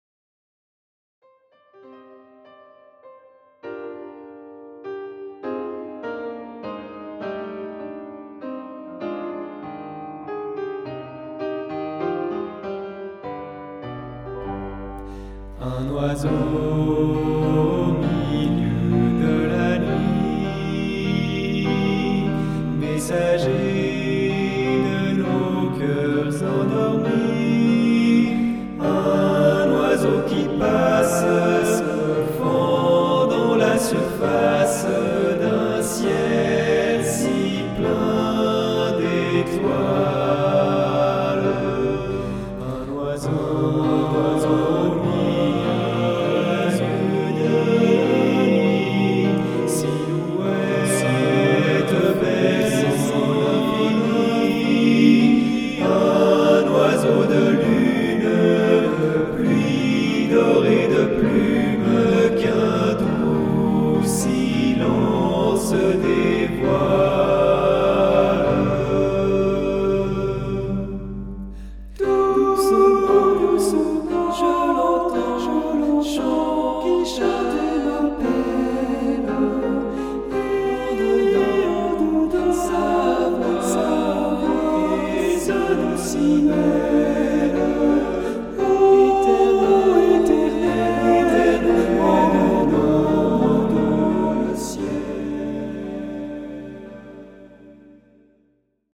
Arrangement pour chœur
Genre : lyrique
Voix : SSAA
Un chant rempli de douceur, aux belles mélodies
Extrait audio (voix réelles)